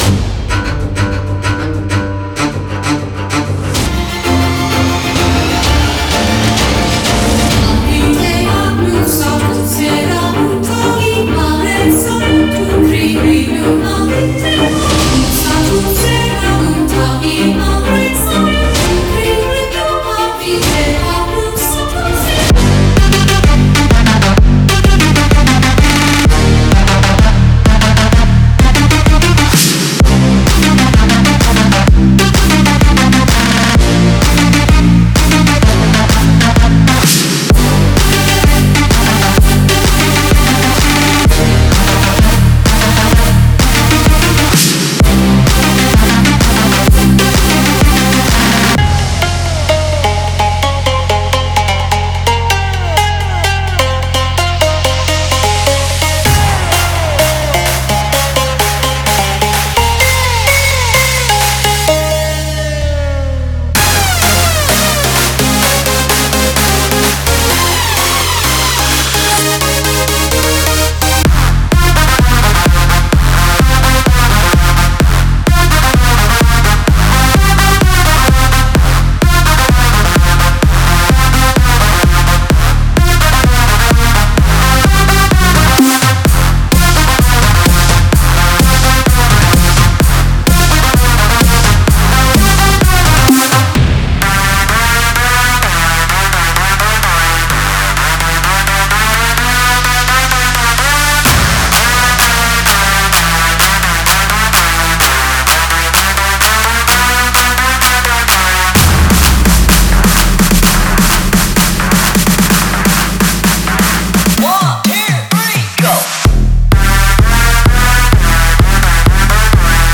完整Demo